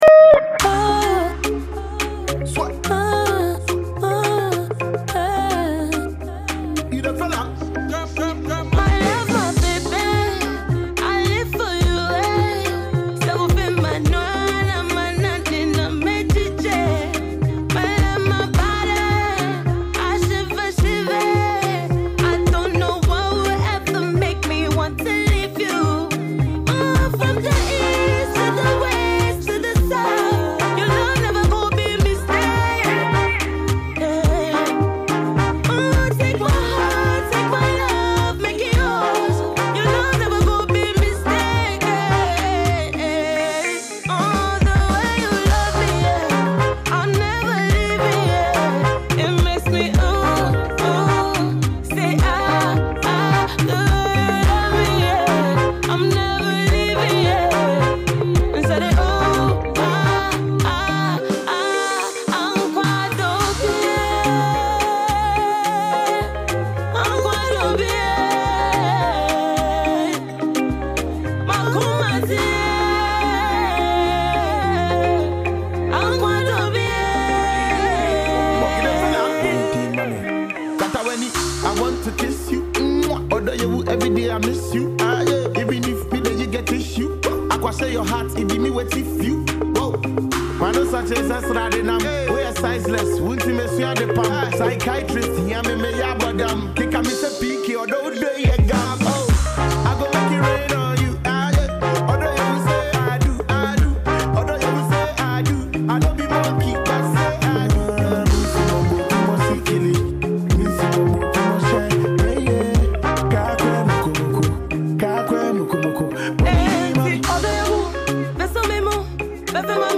dance single